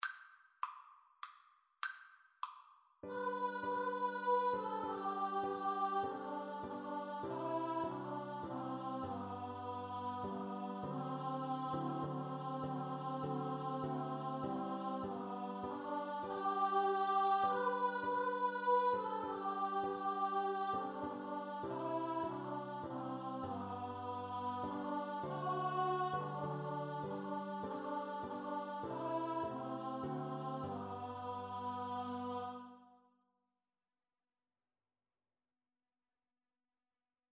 • Choir (SATB)
3/4 (View more 3/4 Music)